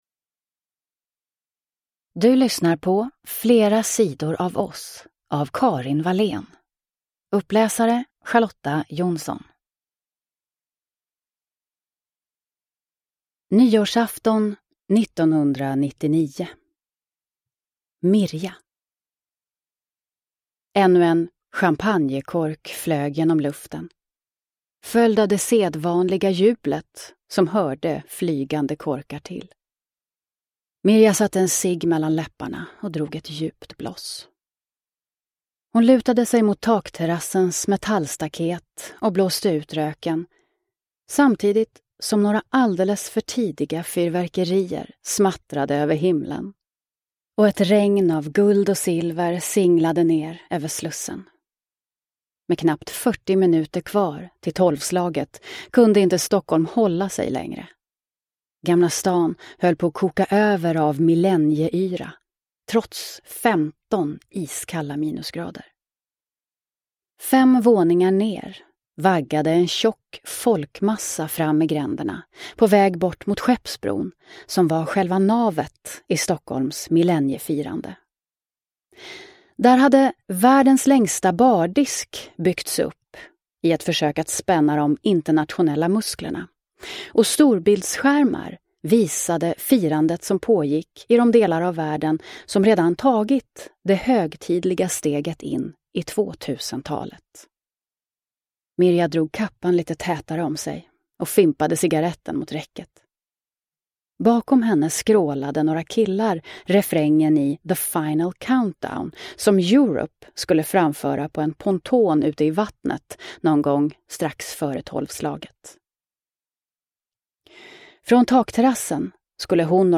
Flera sidor av oss – Ljudbok – Laddas ner